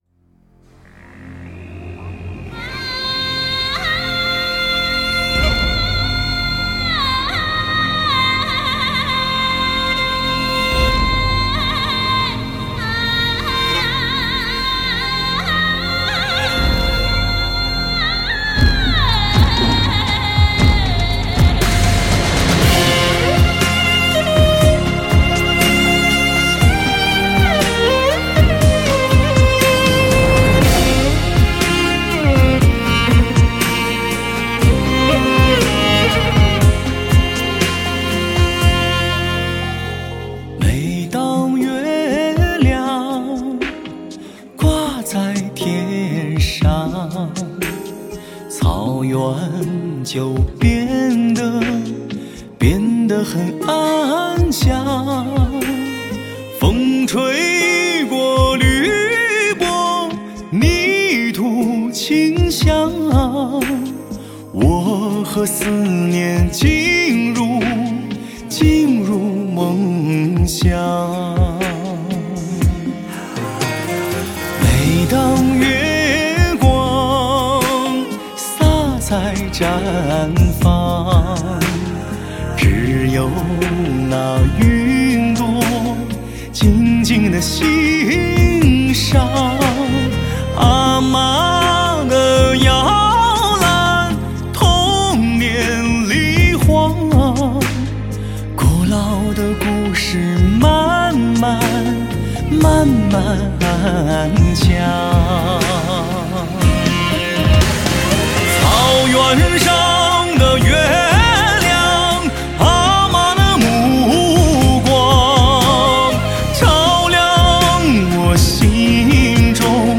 融合不同民族风格 见证心灵歌者传奇色彩